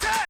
TM88 TakenVox.wav